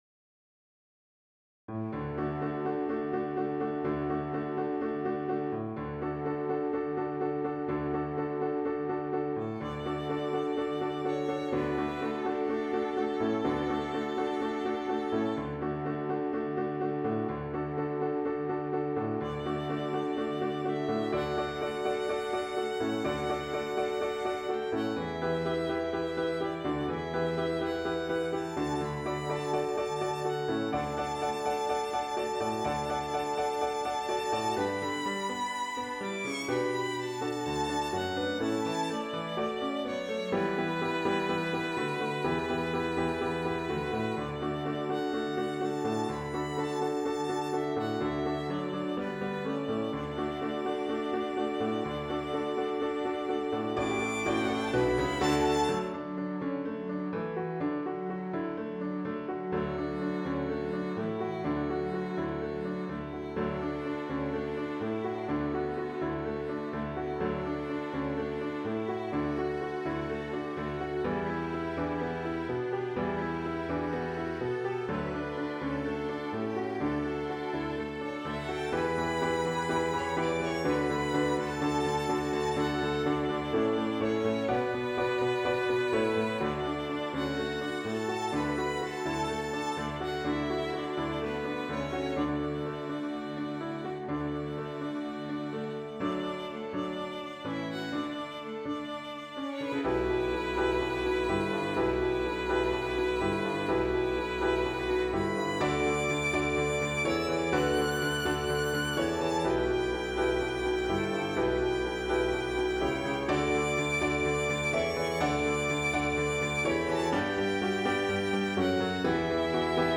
Piano, Violin, Cello
Christian, Gospel, Sacred, Praise & Worship.